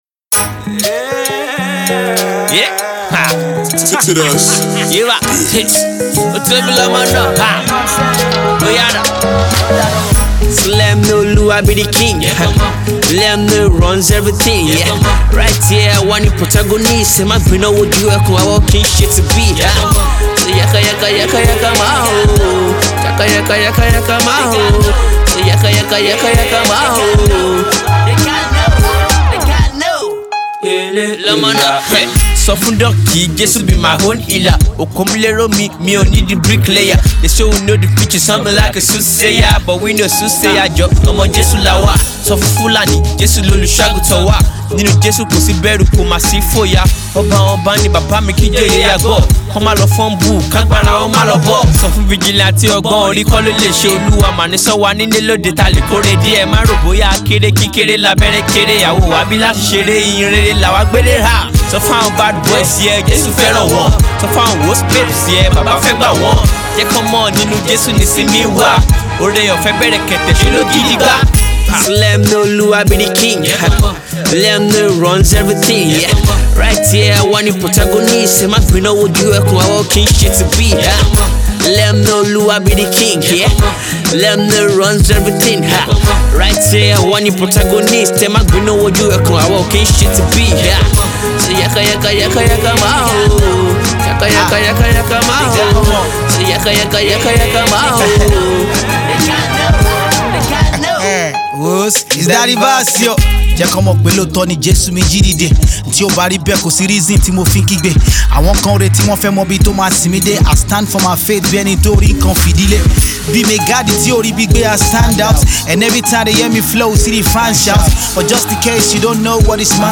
Indigenous Christian Artiste/Rapper